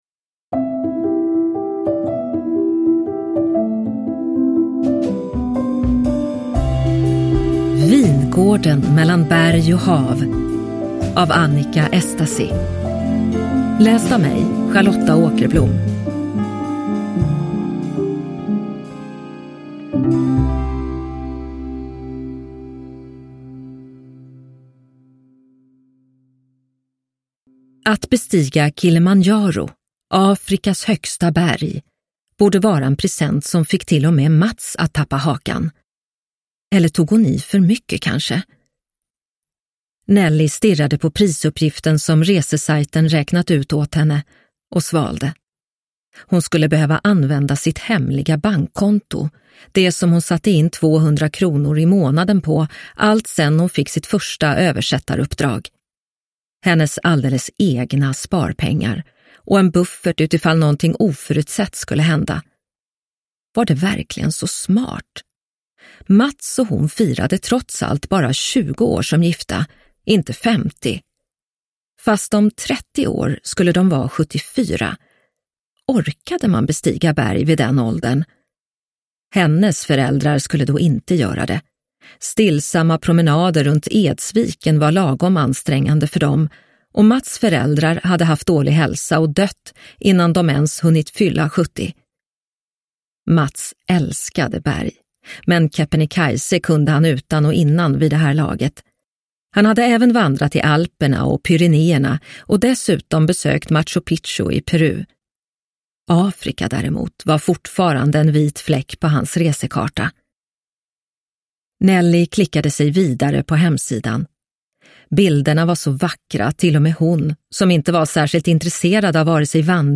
Vingården mellan berg och hav – Ljudbok